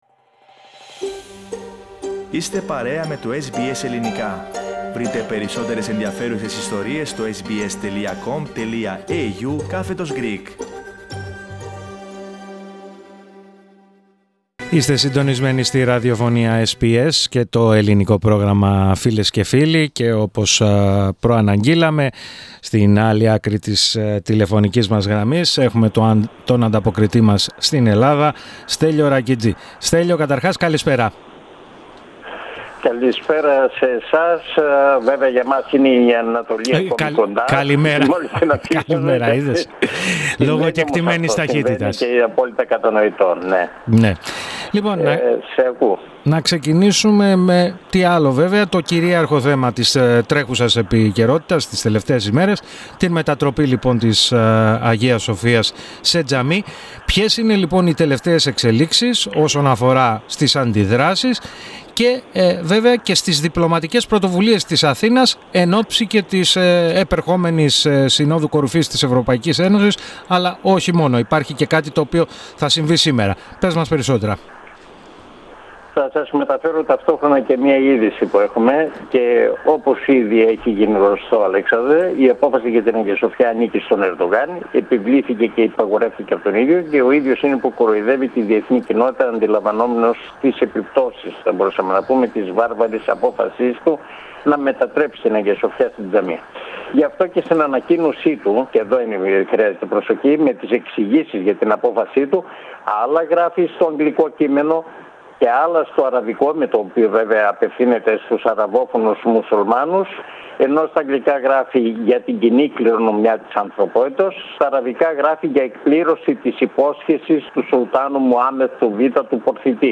Πατήστε Play στην κεντρική φωοτγραφία για να ακούσετε το podcast με την ανταπόκριση από την Ελλάδα ΔEITE AKOMH Δραστική μείωση στις πτήσεις εξωτερικού στην Αυστραλία Share